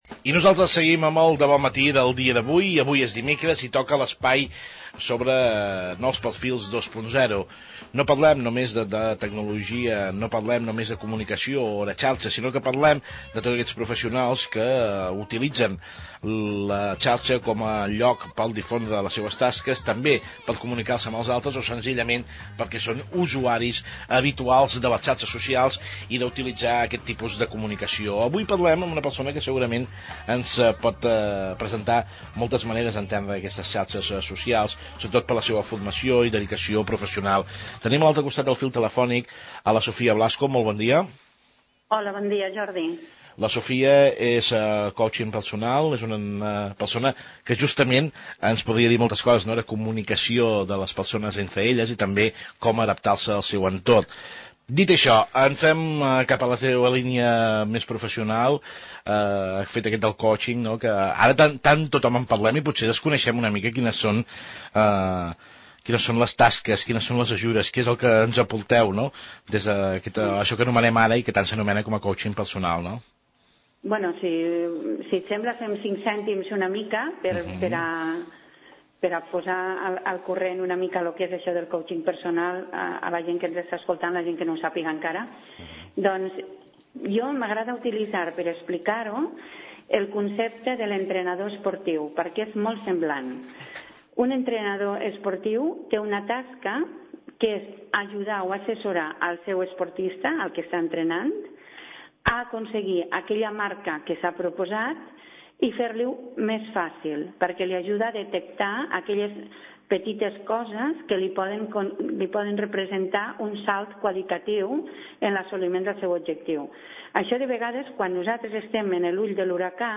Al programa ‘De Bon Matí’ de Punt 6 Ràdio de Reus, parlant de coaching i d’estrès.